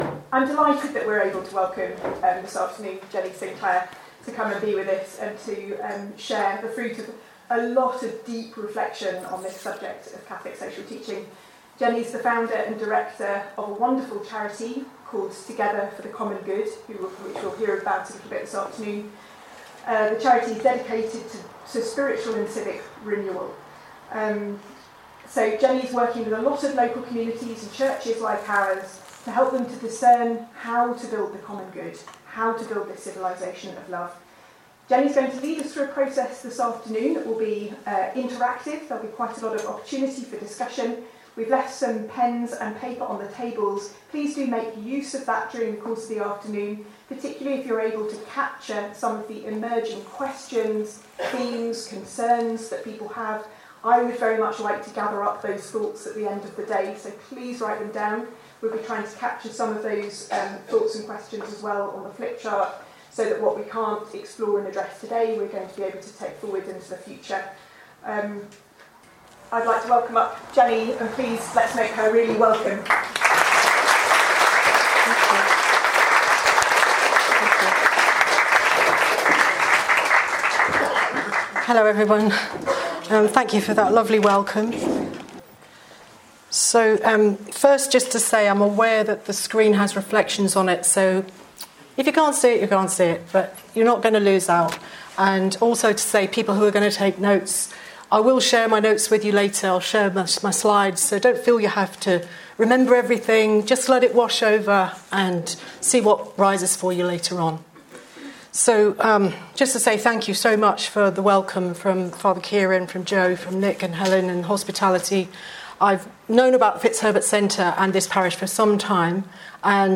It was delivered to East Brighton Parish on 9 March 2025.